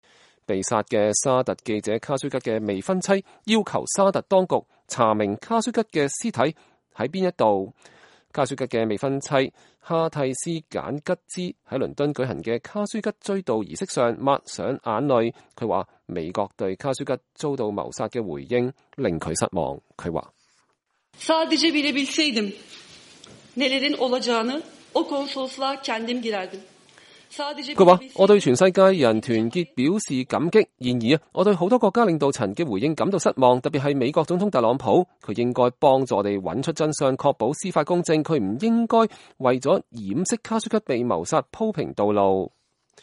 卡舒吉的未婚妻哈蒂絲·簡吉茲在倫敦舉行的卡舒吉追悼儀式上抹去眼淚，她說美國對卡舒吉遭謀殺的回應令她失望。